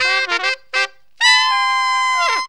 HORN RIFF 7.wav